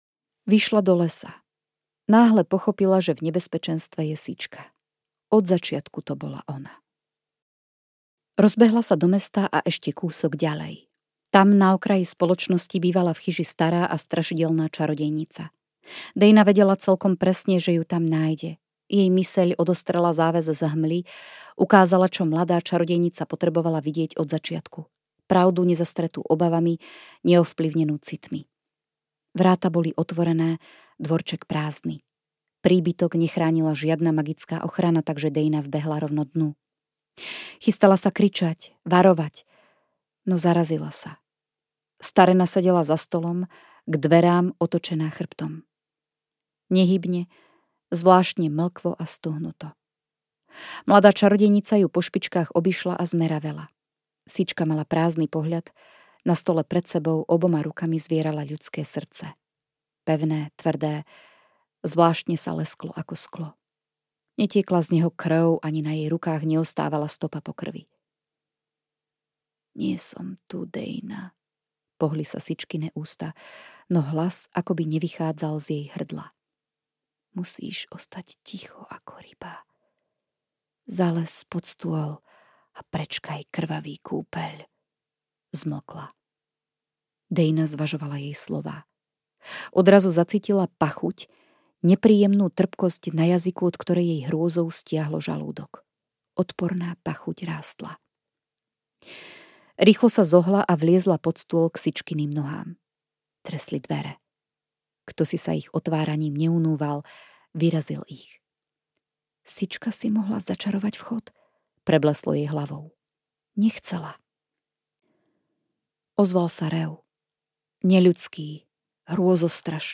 Svedomie temnej časti audiokniha
Ukázka z knihy